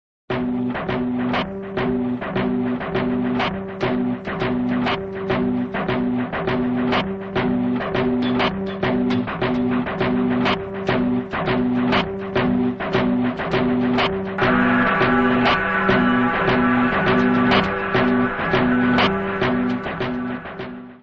processed guitar, sampler